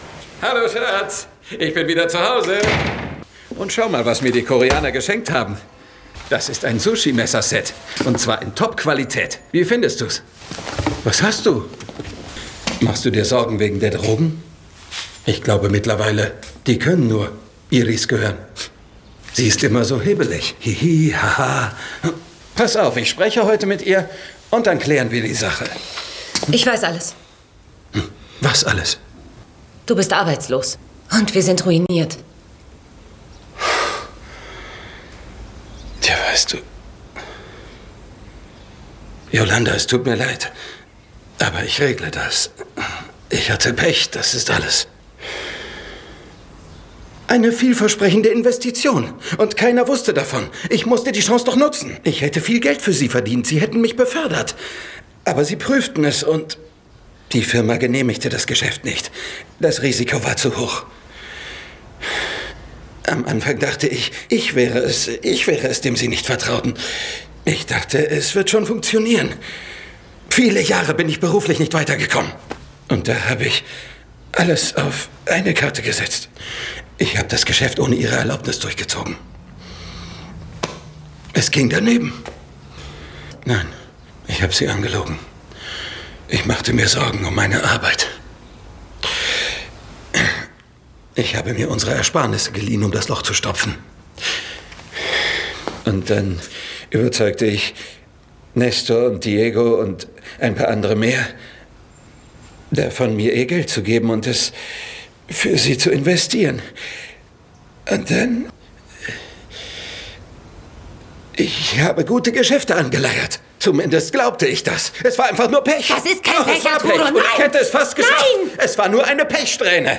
Mittel plus (35-65)
Lip-Sync (Synchron)